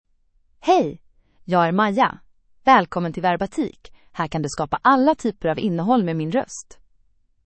MayaFemale Swedish AI voice
Maya is a female AI voice for Swedish (Sweden).
Voice sample
Listen to Maya's female Swedish voice.
Female
Maya delivers clear pronunciation with authentic Sweden Swedish intonation, making your content sound professionally produced.